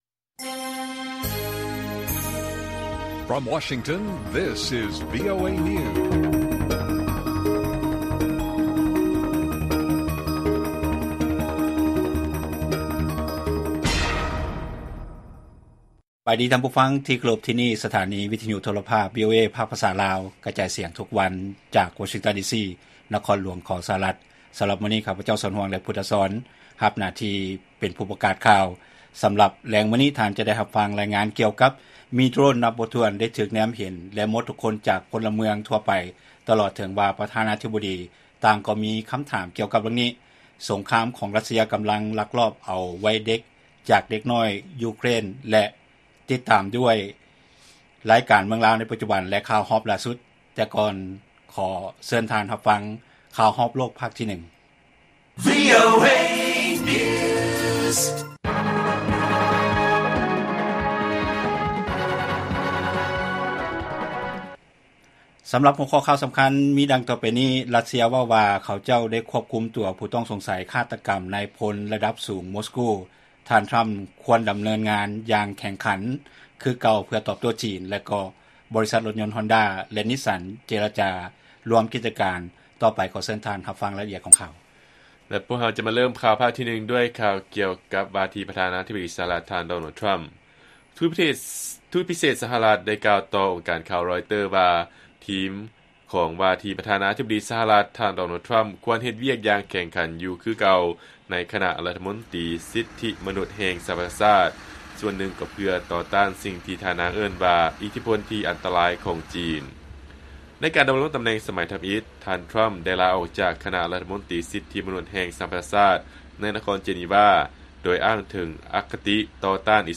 ລາຍການກະຈາຍສຽງຂອງວີໂອເອ ລາວ ວັນທີ 18 ທັນວາ 2024